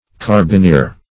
Carbineer \Car`bi*neer"\, n. [F. carabinier.] (Mil.)